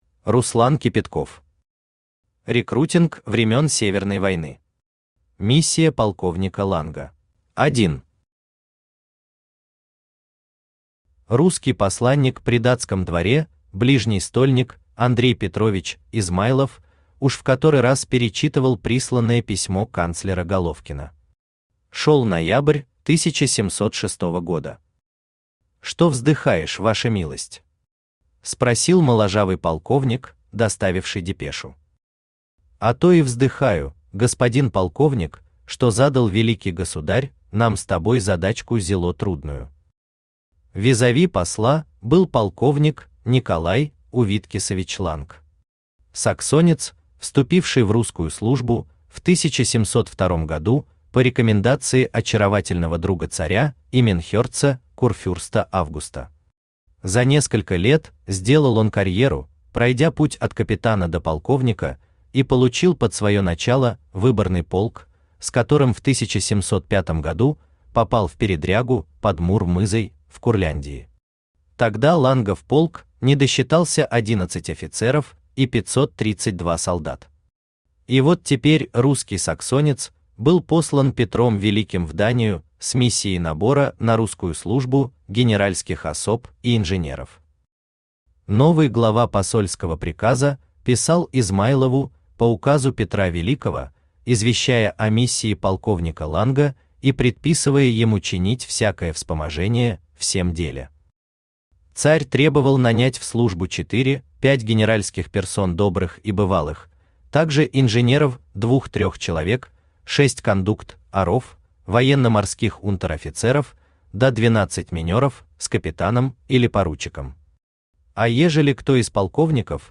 Аудиокнига Рекрутинг времен Северной войны. Миссия полковника Ланга | Библиотека аудиокниг
Миссия полковника Ланга Автор Руслан Кипятков Читает аудиокнигу Авточтец ЛитРес.